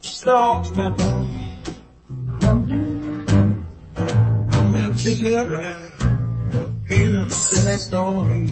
blues_blues.00009.mp3